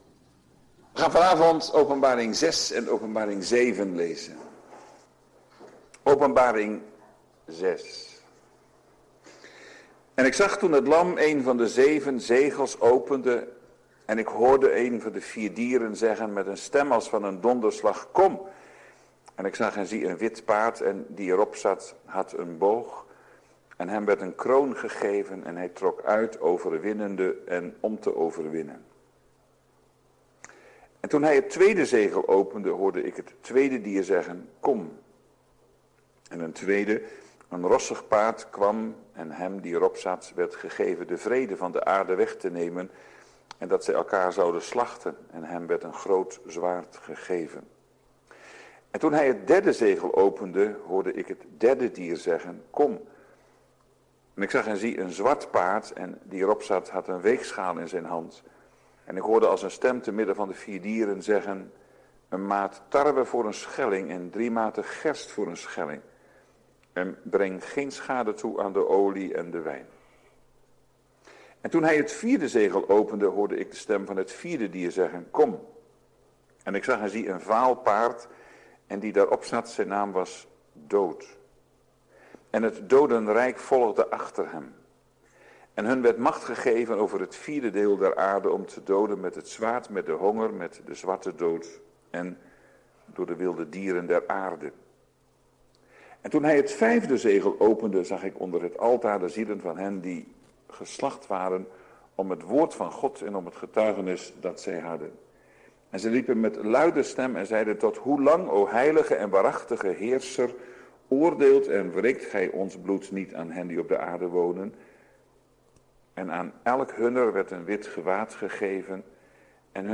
Na ongeveer 45 minuten (wanneer kant A overgaat naar kant B) kan een korte stilte voorkomen.